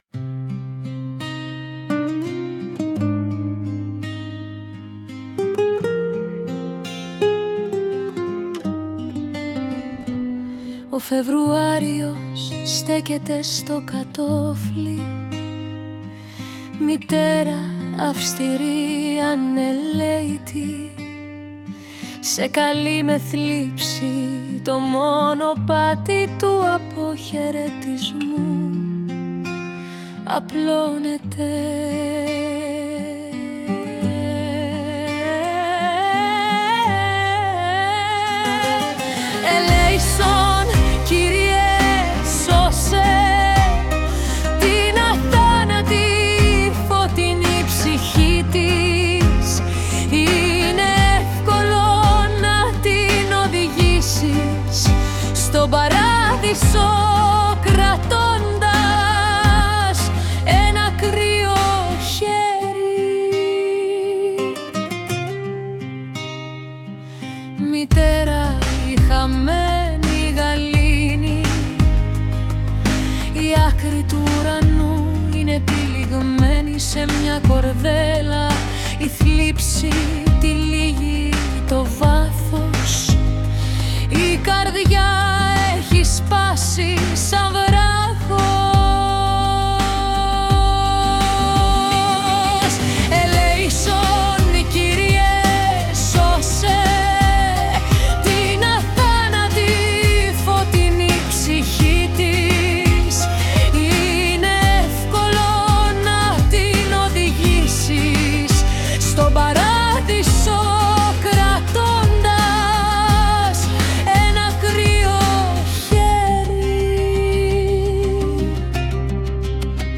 текст авторський...музика і виконання ШІ